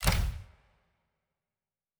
Special Click 21.wav